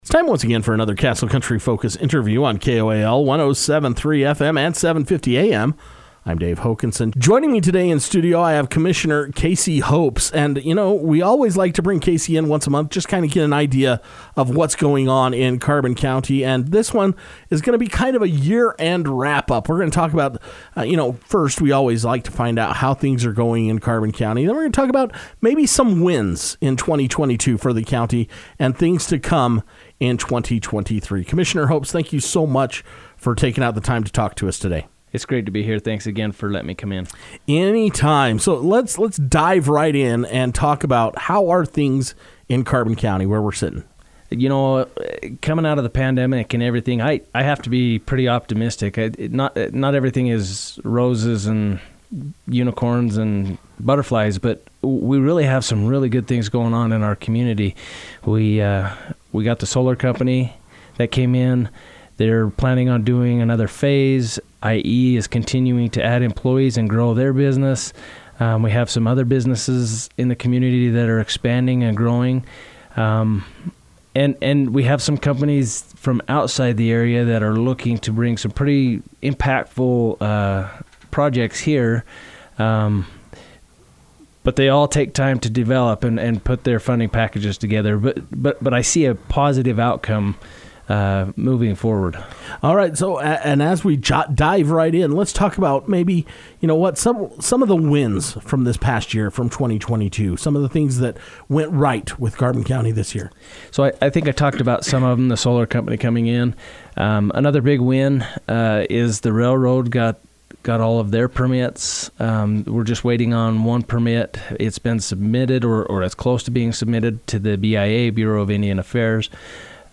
With the year coming to an end and looking back to see what good things have been taken place in the county Castle Country Radio sat down with Carbon County Commissioner Casey Hopes to reflect on the past year.